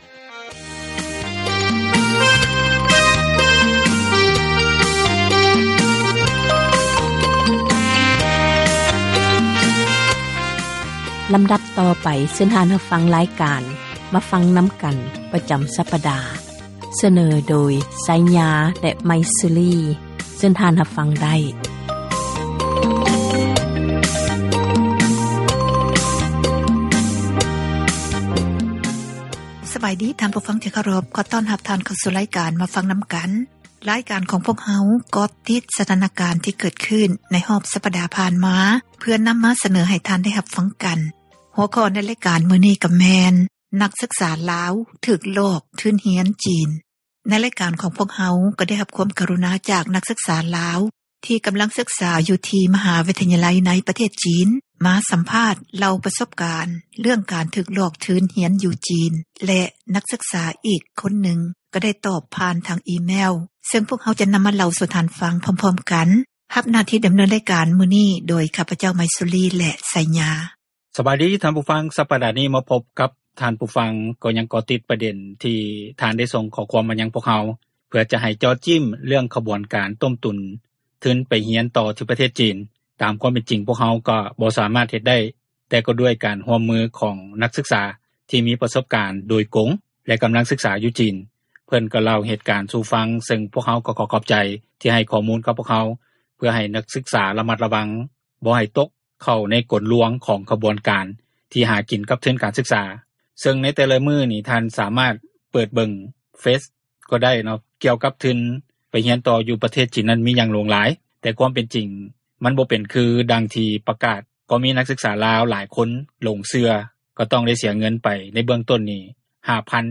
ການສົນທະນາ ໃນບັນຫາ ແລະ ຜົລກະທົບ ຕ່າງໆ ທີ່ເກີດຂຶ້ນ ຢູ່ ປະເທດລາວ.